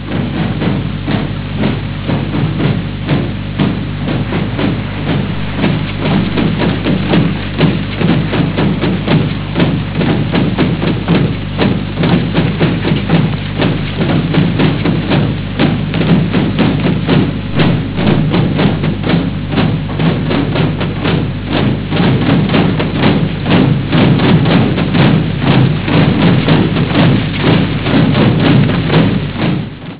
Original track music:
Tamburi di guerra (0.30 - 236 KB wav)